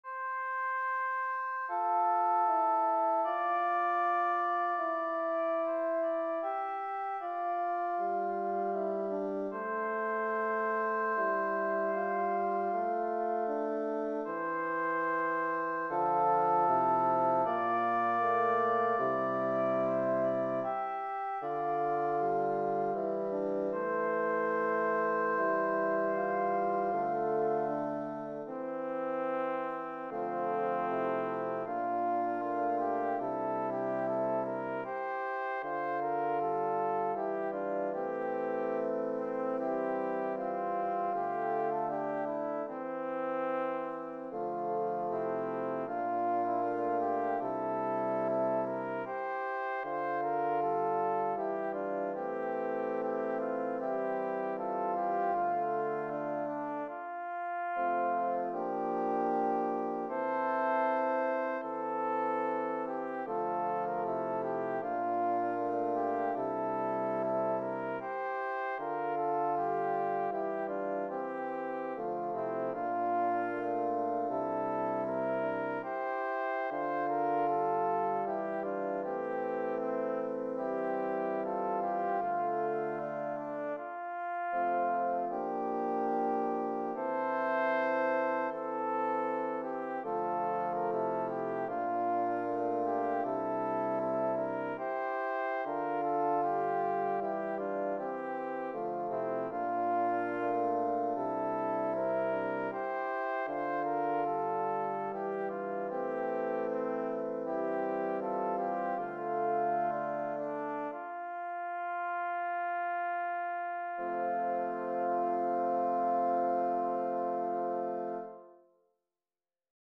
Voicing/Instrumentation: SATB We also have other 1 arrangement of " A Special Gift is Kindness ".
Vocal Solo Medium Voice/Low Voice Choir with Soloist or Optional Soloist